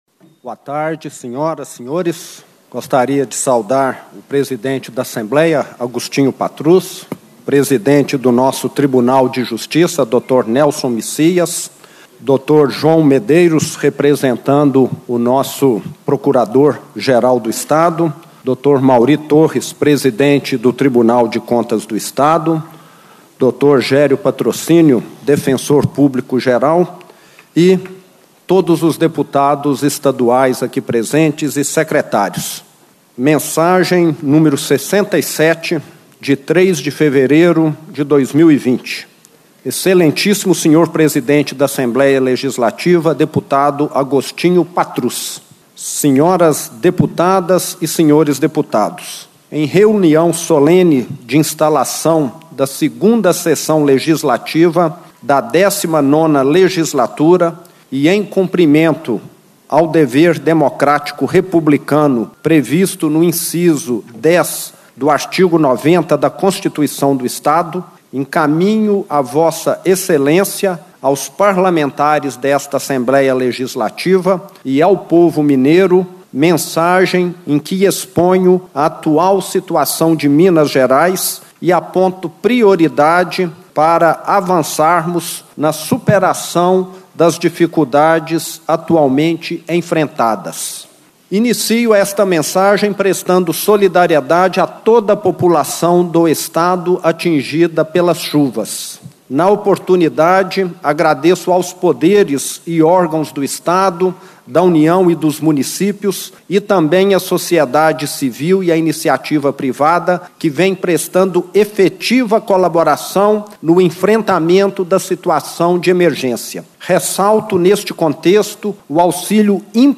Na instalação da 2ª Sessão Legislativa da 19ª Legislatura, o Governador de Minas destacou, em discurso, a necessidade do Legislativo aprovar projetos, como a adesão ao regime de recuperação fiscal e a reforma da previdência.
Discursos e Palestras